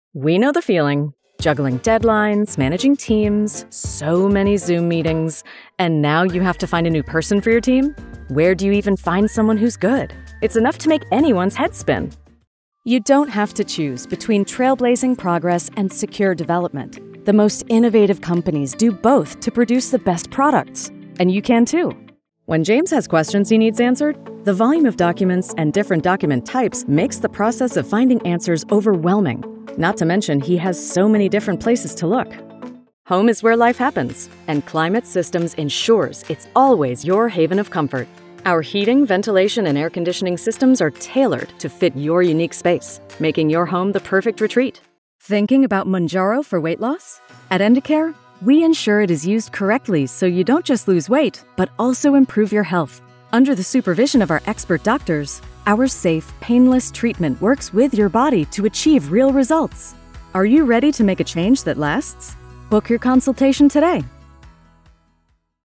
Professional Female Voiceover Artist